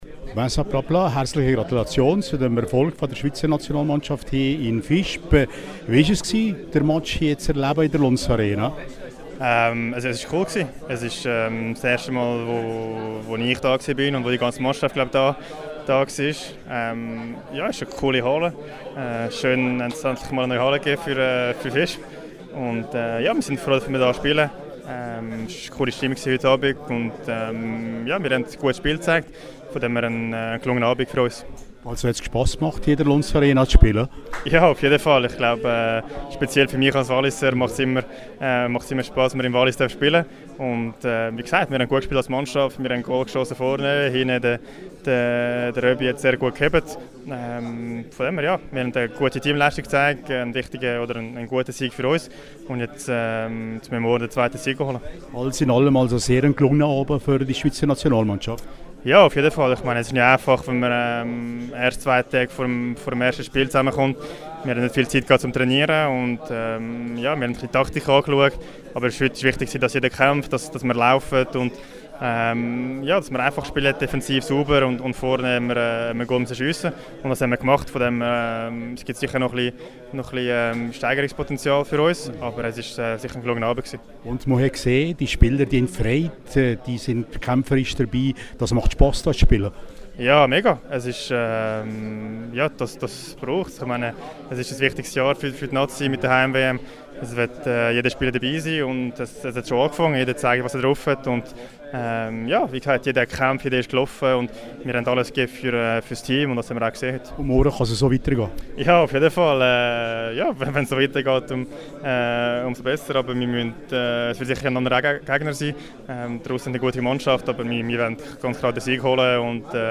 Das Team von Trainer Fischer trifft nun am Freitag im Finalspiel auf Russland./en Interview mit Vincent Praplan (Quelle: rro)